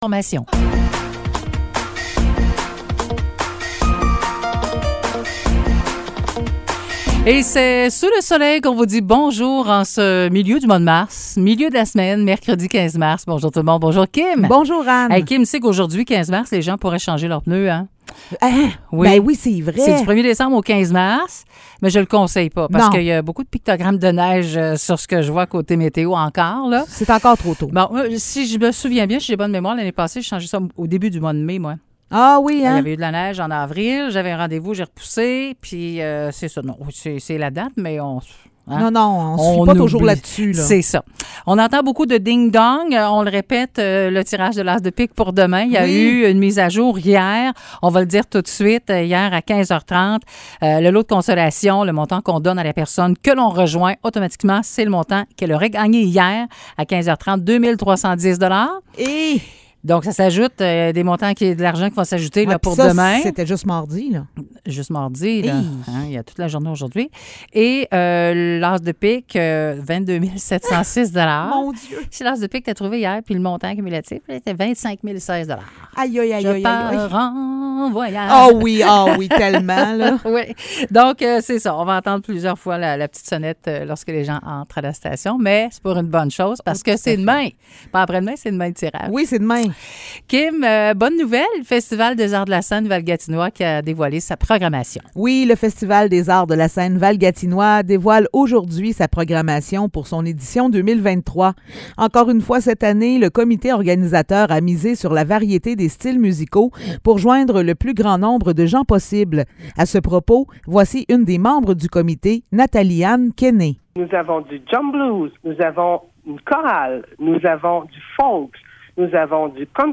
Bulletins de nouvelles